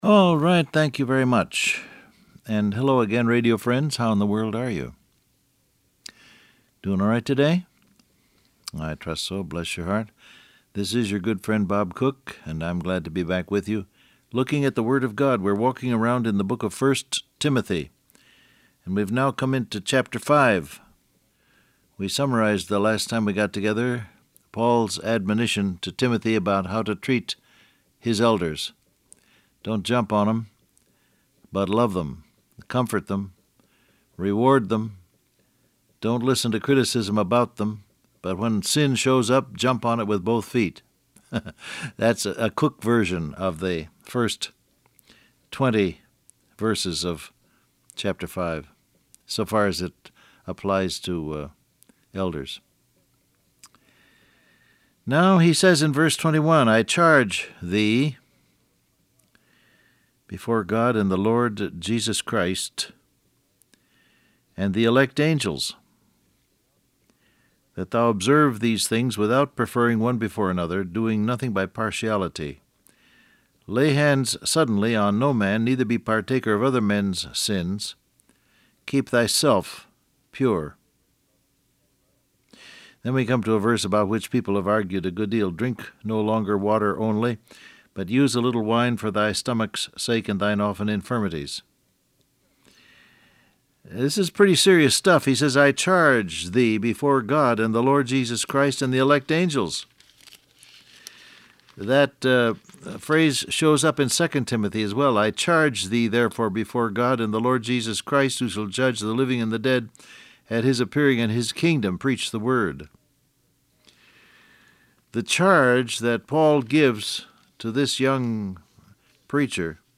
Download Audio Print Broadcast #6747 Scripture: 1 Timothy 5:21-23 , John 10, Colossians 3:17 Topics: Authority , Responsible , Angels , Charge Transcript Facebook Twitter WhatsApp Alright, thank you very much.